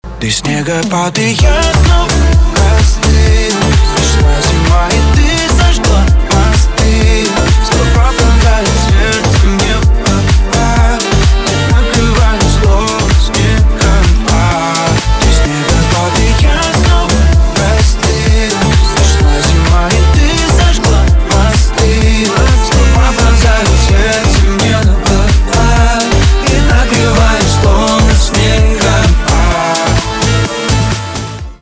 поп
ремиксы
грустные
чувственные , битовые , басы